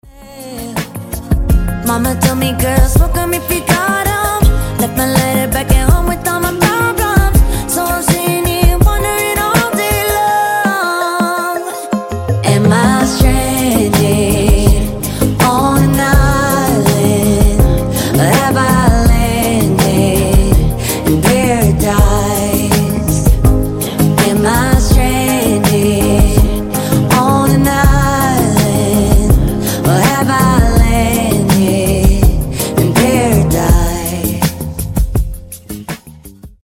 Медленные Спокойные
Поп